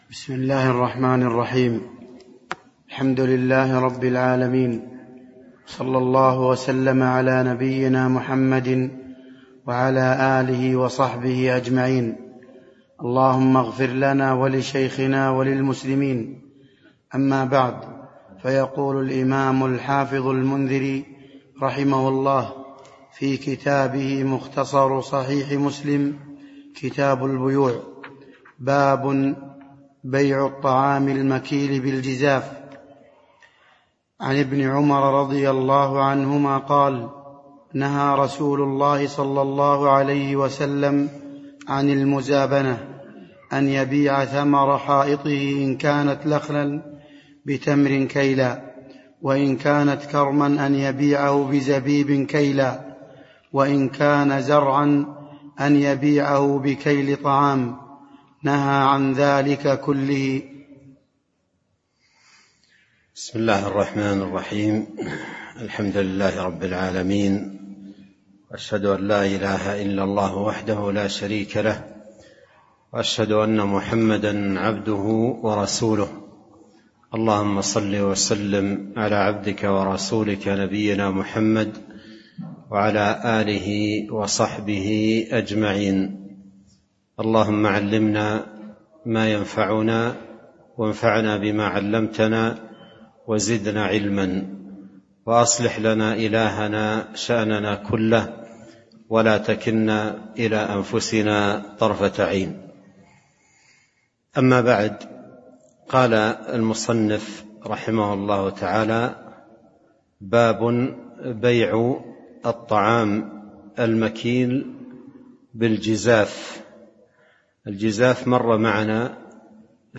تاريخ النشر ١ صفر ١٤٤٣ هـ المكان: المسجد النبوي الشيخ: فضيلة الشيخ عبد الرزاق بن عبد المحسن البدر فضيلة الشيخ عبد الرزاق بن عبد المحسن البدر باب بيع الطعام المكيل بالجزاف (02)